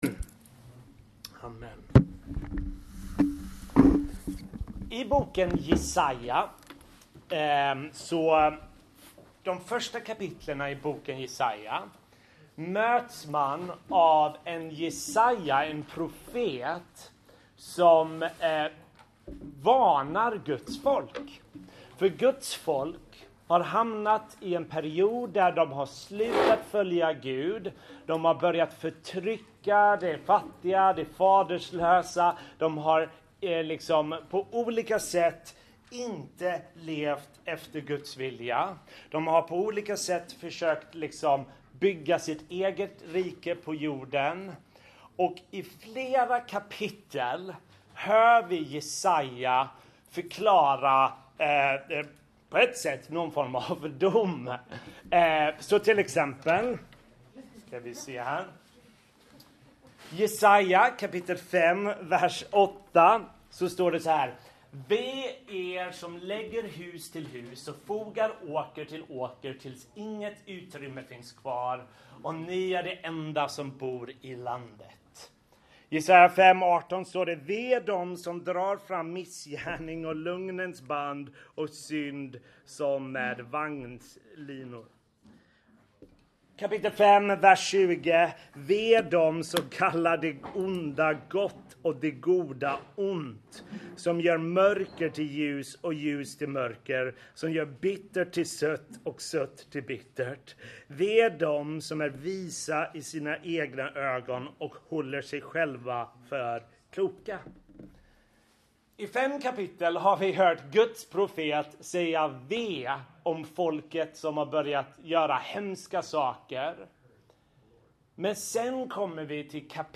Bibelstudium